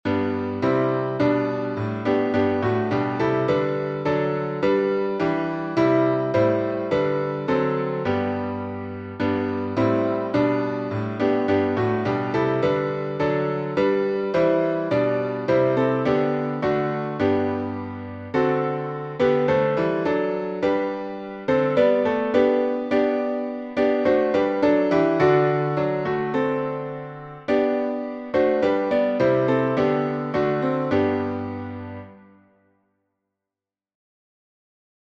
#5026: Guide Me, O Thou Great Jehovah — alternate chording | Mobile Hymns